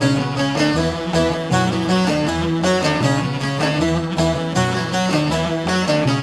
SAZ 02.AIF.wav